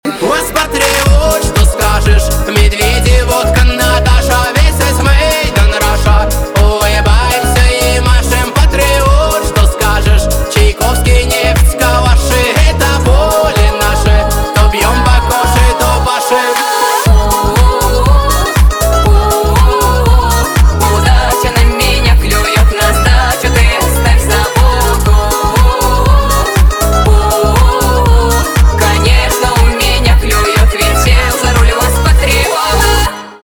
поп
битовые , басы , качающие , танцевальные